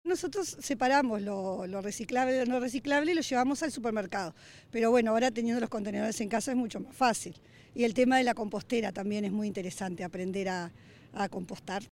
Otra vecina de la ciudad